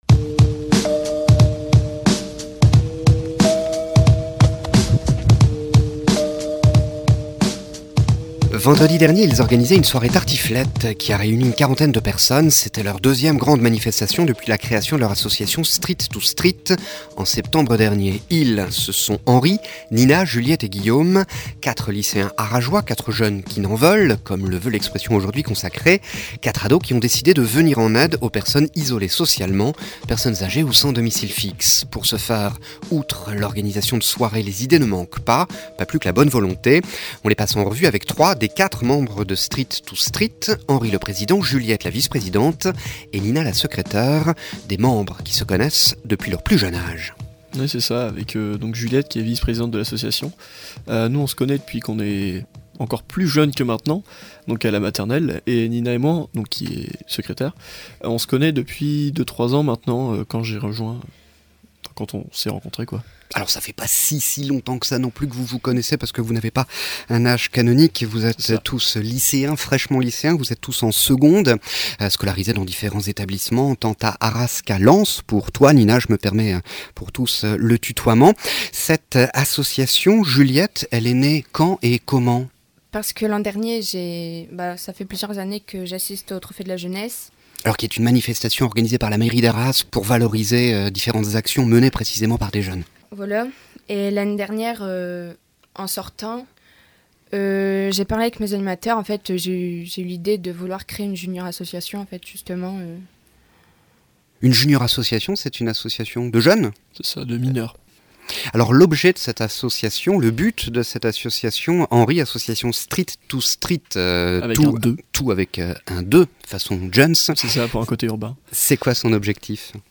STREET 2 STREET REPORTAGES/ENTRETIENS